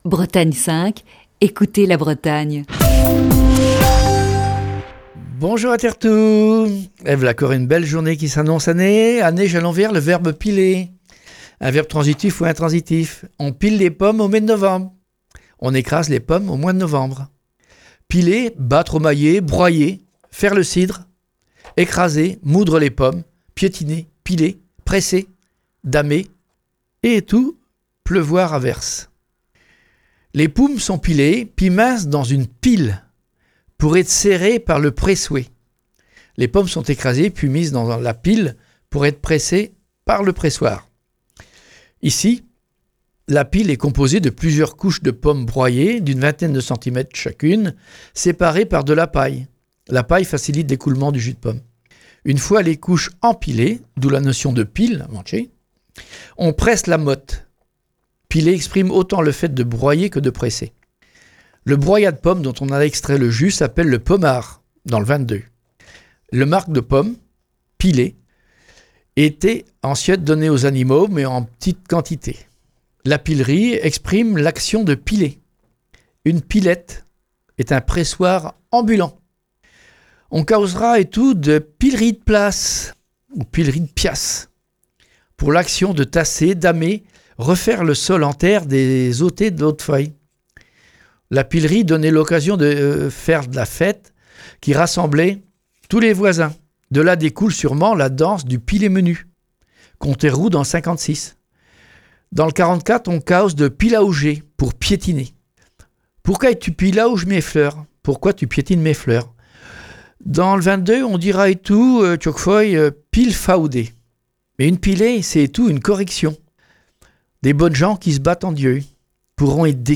Chronique du 25 août 2020.